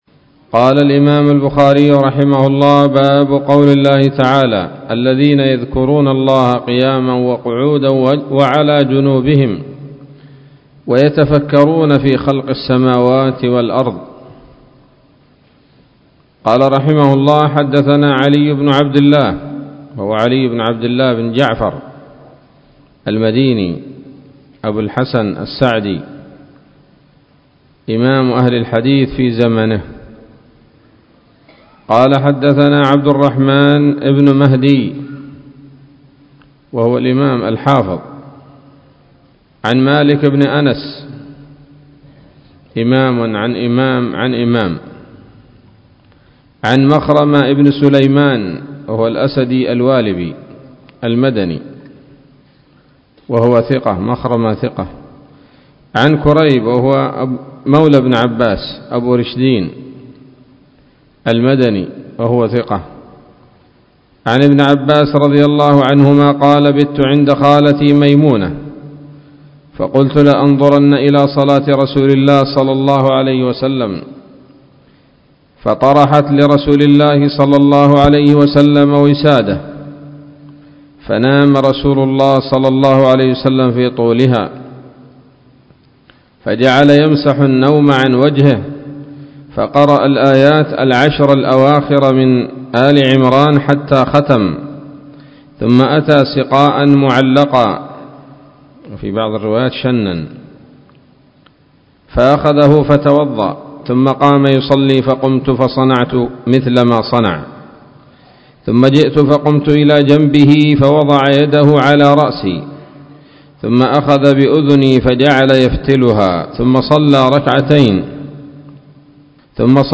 الدرس الحادي والستون من كتاب التفسير من صحيح الإمام البخاري